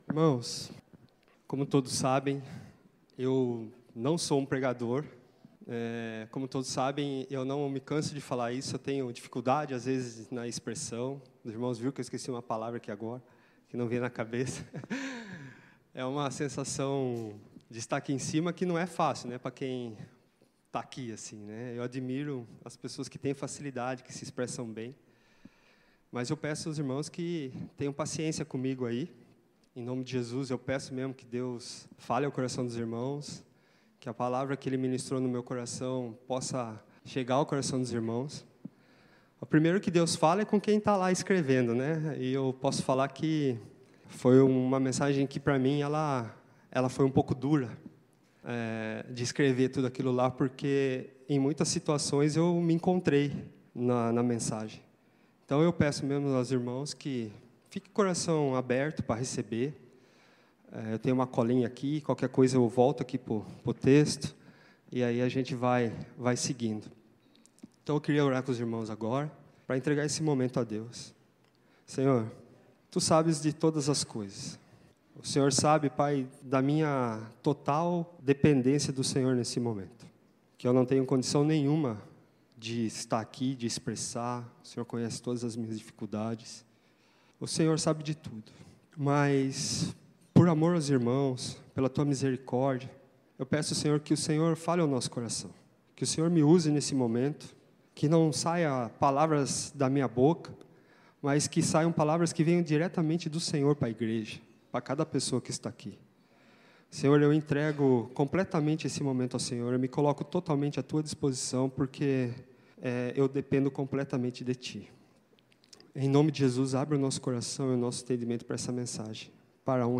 Primeira Igreja Batista de Brusque